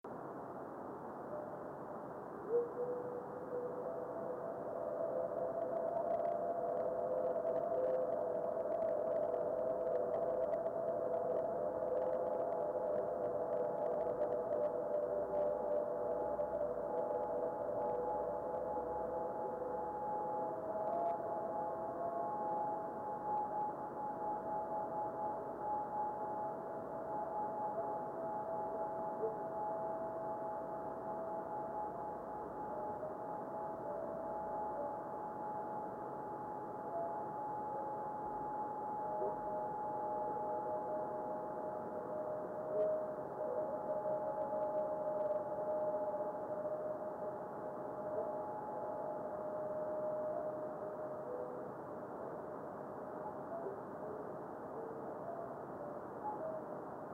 video and stereo sound:
Another minimal head echo then long ionization reflection.